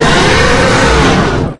Monster4.ogg